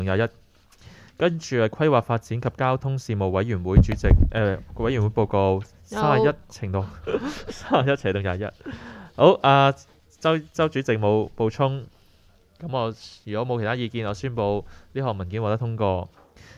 区议会大会的录音记录
深水埗区议会会议室